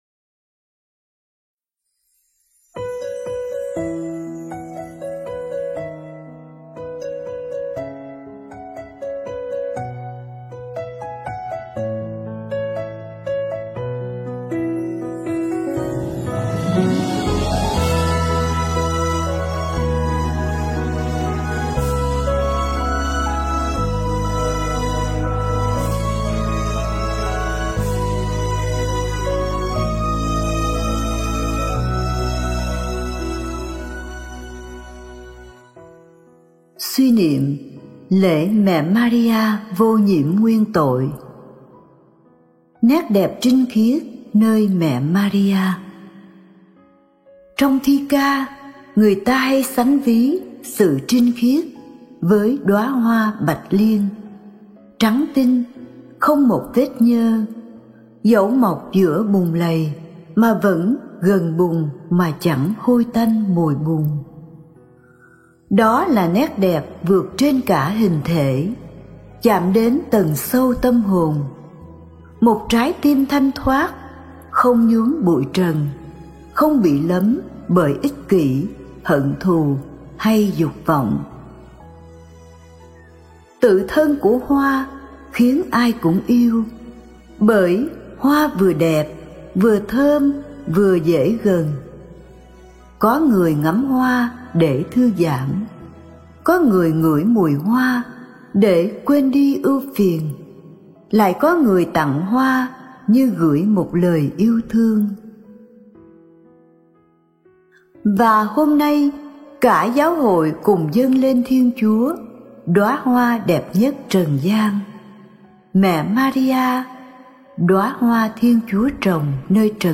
Nét đẹp trinh khiết nơi Mẹ Maria - (Suy niệm lễ Mẹ Vô Nhiễm Nguyên Tội - 2025)